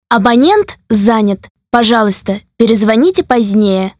abonent_zanyat(female)
abonent_zanyatfemale.mp3